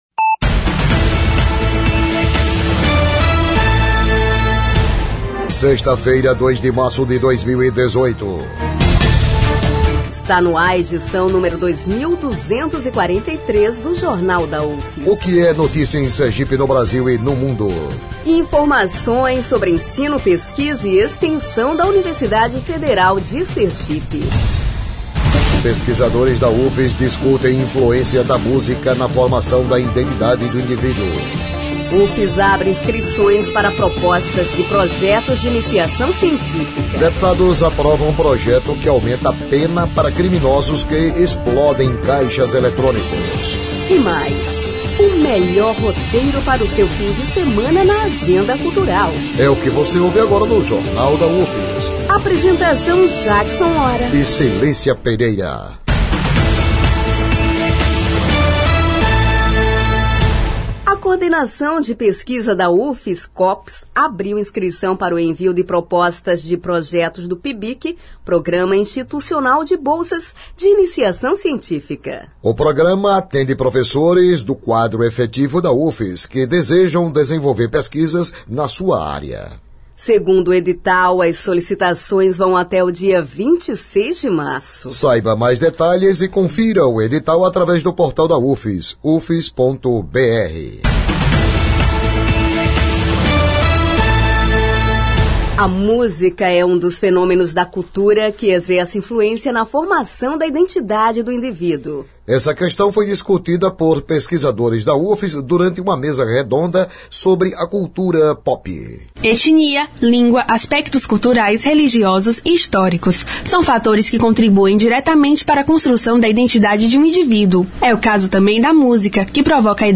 O noticiário vai ao ar às 11h na Rádio UFS, com reprises às 17h e 22h.